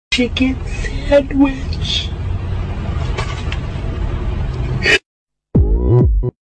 nikocado avocado saying chicken sandwich then yells gangnam style